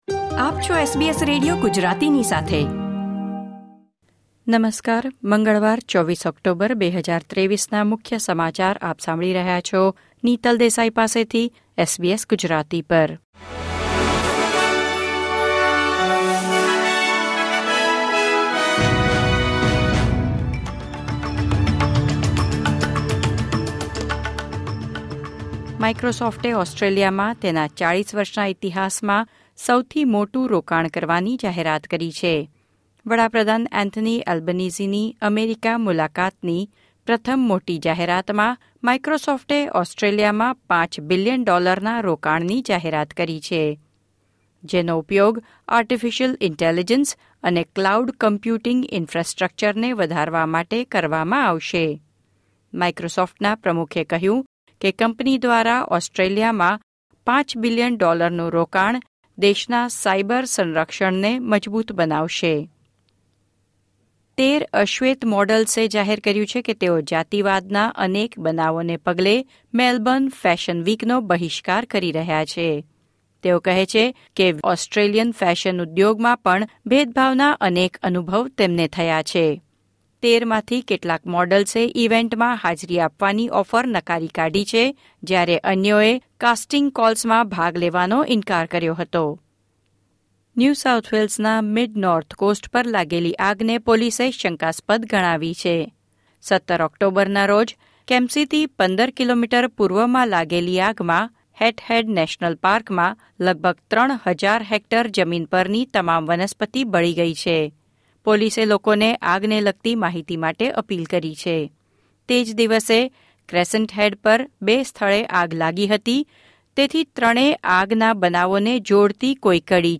SBS Gujarati News Bulletin 24 October 2023